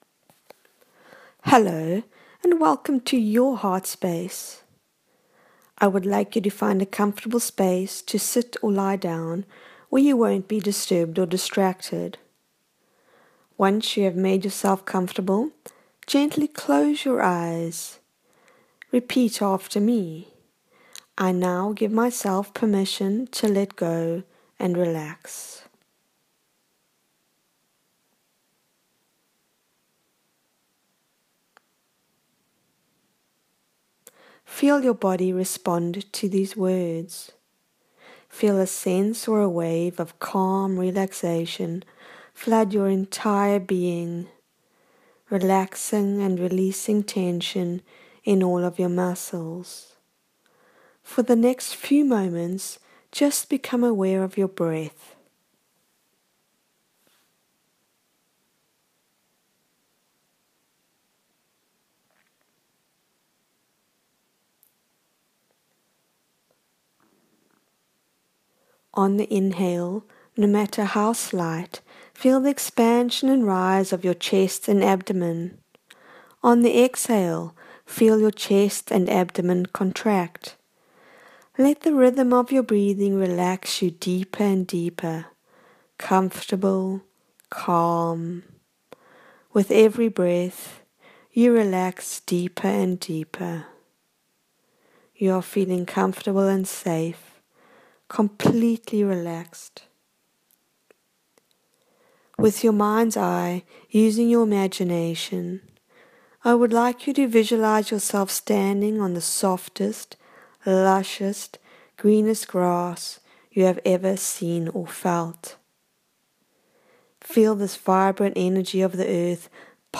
This is a 9-minute guided visualization journey, working with our healing Mother Earth.
healing-with-mother-earth-journey.m4a